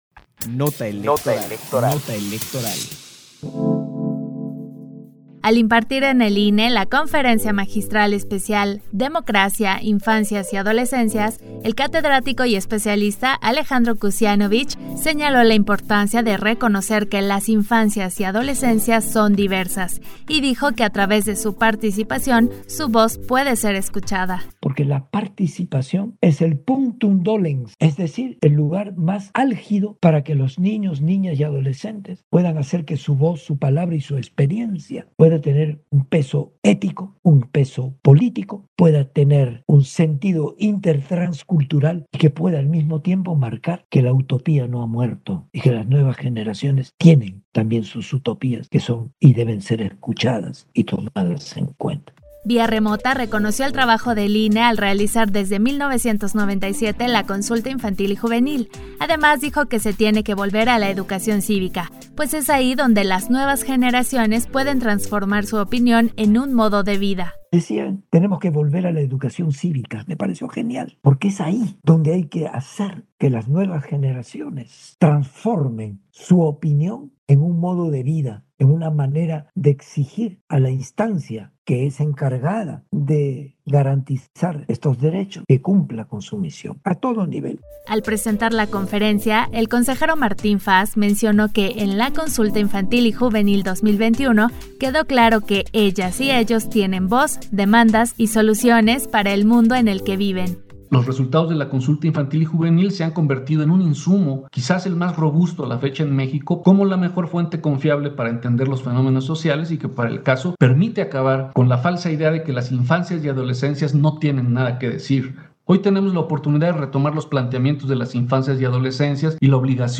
Conferencia Magistral Especial